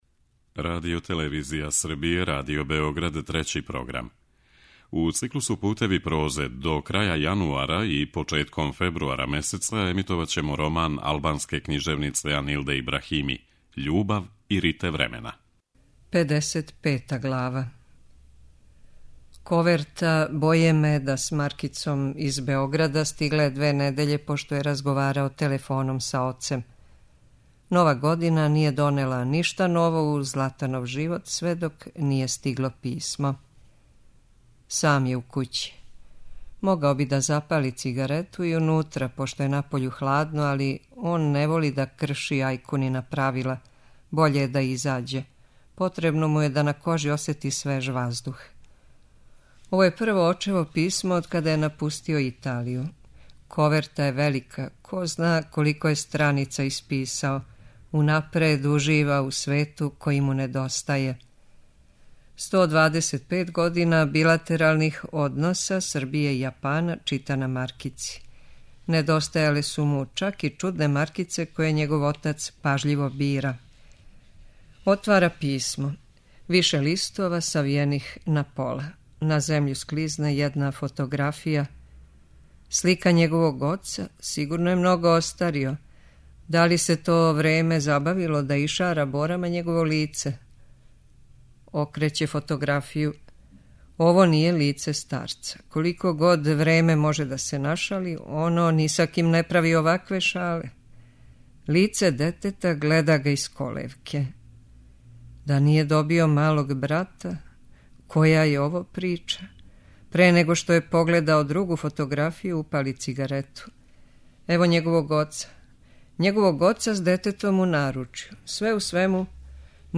преузми : 14.02 MB Књига за слушање Autor: Трећи програм Циклус „Књига за слушање” на програму је сваког дана, од 23.45 сати.